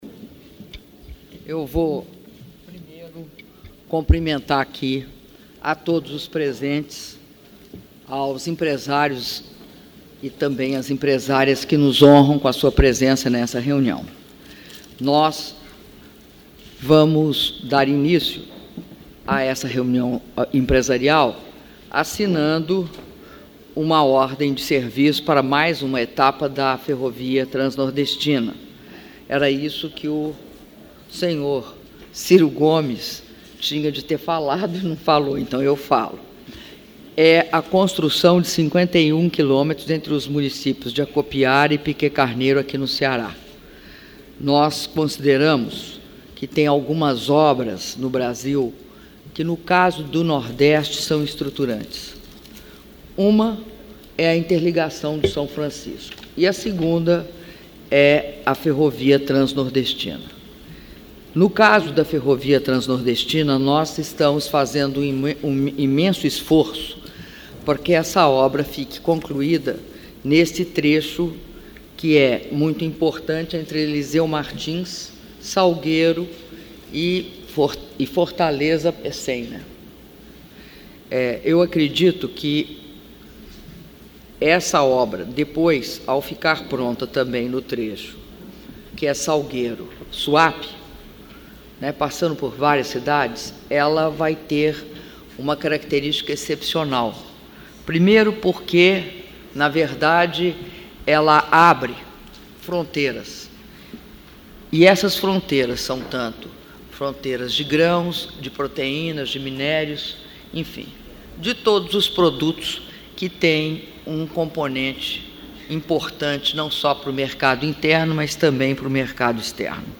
Áudio do discurso da Presidenta da República, Dilma Rousseff, na abertura da reunião com empresários - Fortaleza/CE (08min06s)